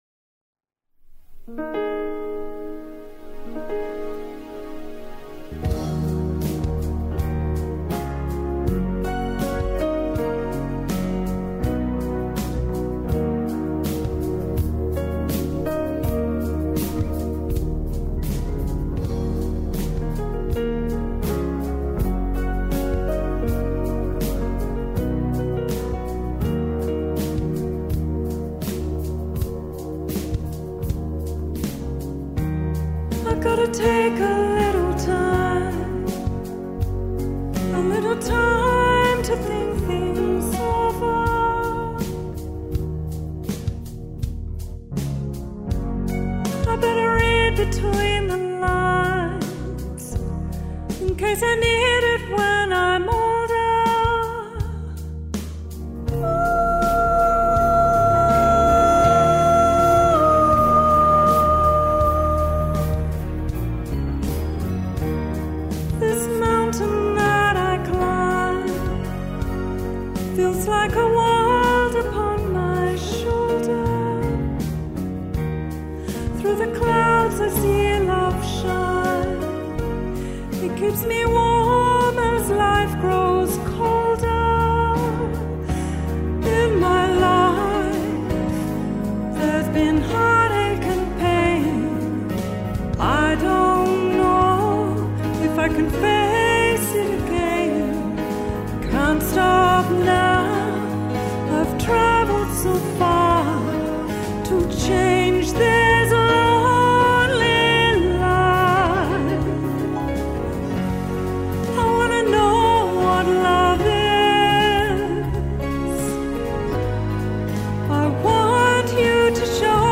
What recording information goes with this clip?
Demo tracks recorded & mixed in Vancouver, BC Canada at: Studio Recorded November, 2017